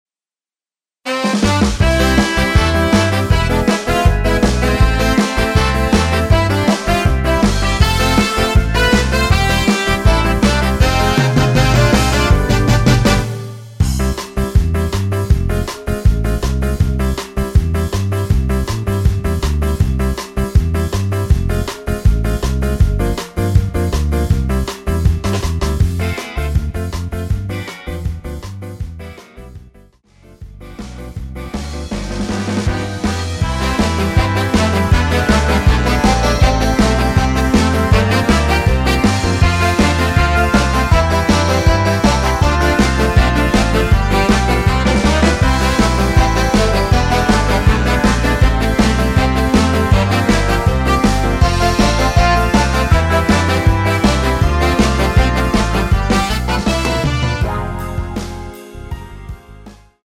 음정은 반음정씩 변하게 되며 노래방도 마찬가지로 반음정씩 변하게 됩니다.
앞부분30초, 뒷부분30초씩 편집해서 올려 드리고 있습니다.
중간에 음이 끈어지고 다시 나오는 이유는
곡명 옆 (-1)은 반음 내림, (+1)은 반음 올림 입니다.